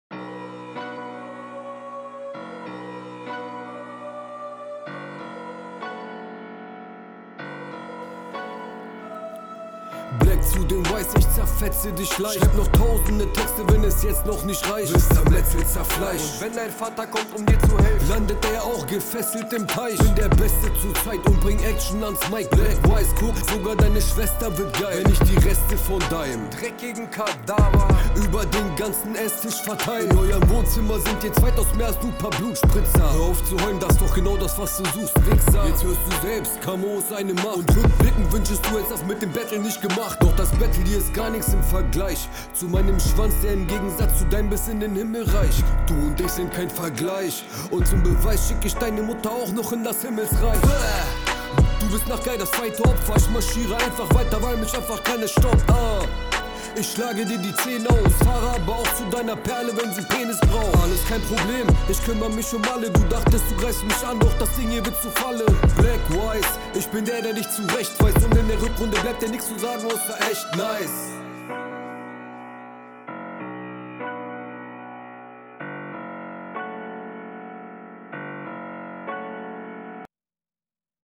bisschen zu leise im Gegensatz zum Beat, trotzdem bisschen anhörbarer als der Gegner Punchline und …